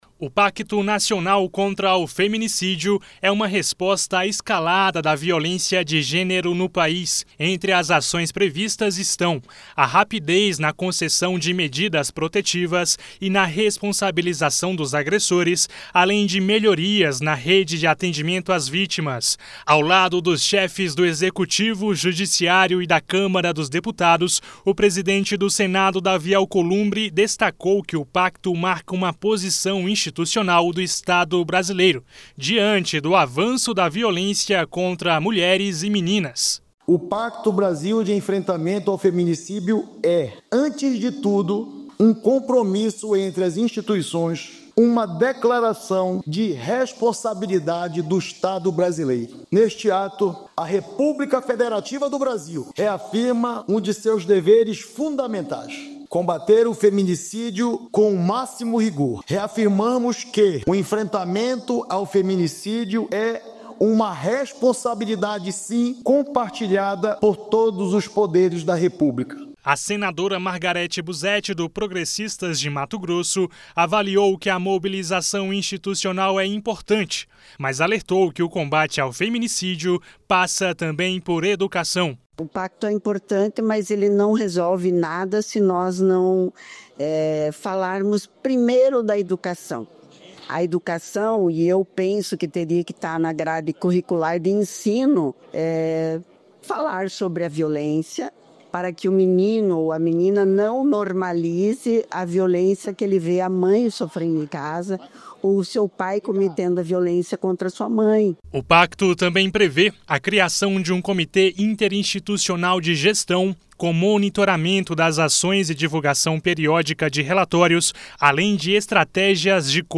Durante o lançamento do Pacto Nacional contra o Feminicídio, o presidente do Senado, Davi Alcolumbre, defendeu a atuação conjunta dos Poderes, enquanto a senadora Margareth Buzetti destacou o papel da educação no combate à violência.